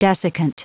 Prononciation du mot desiccant en anglais (fichier audio)
Prononciation du mot : desiccant